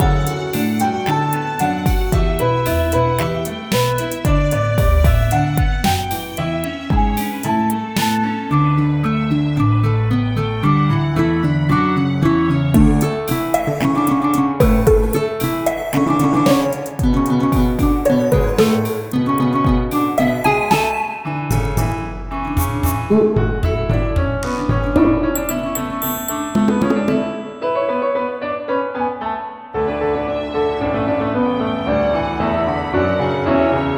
A short looping tune originally envisioned for a pub.2025 remix is the first download and adds a drumbeat plus parts B and C to enhance loopability.